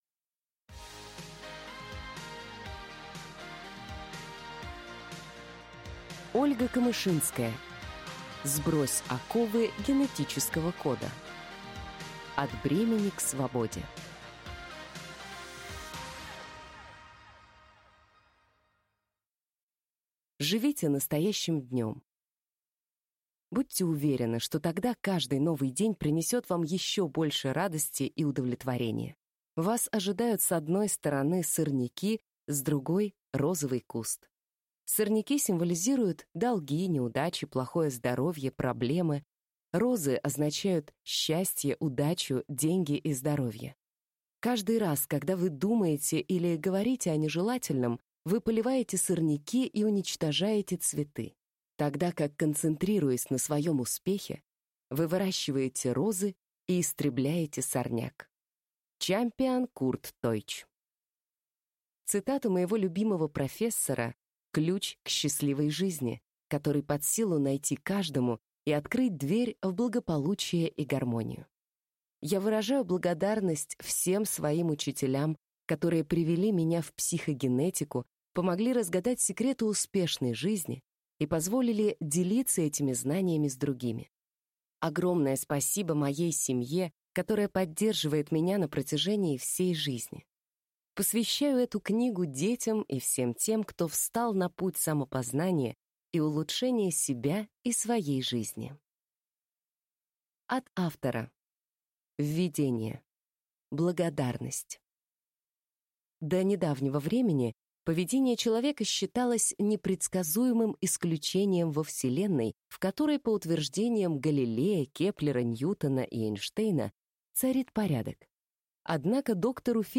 Аудиокнига Сбрось оковы генетического кода | Библиотека аудиокниг